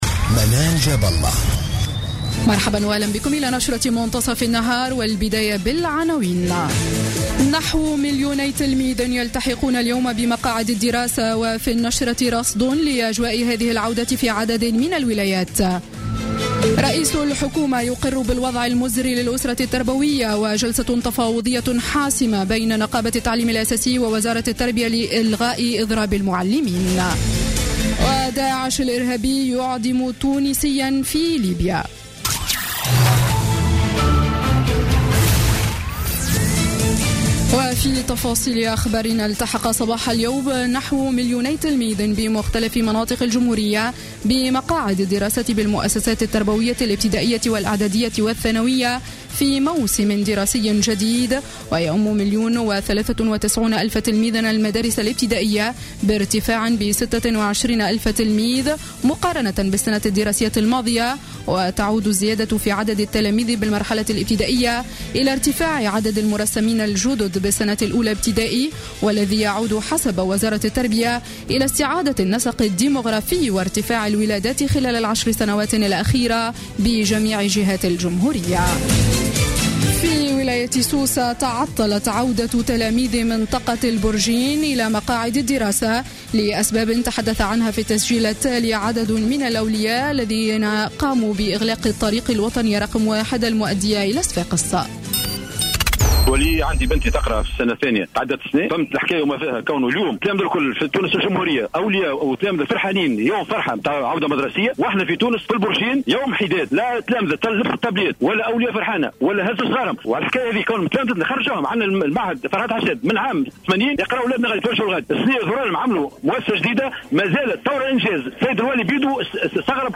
نشرة أخبار منتصف النهار ليوم الثلاثاء 15 سبتمبر 2015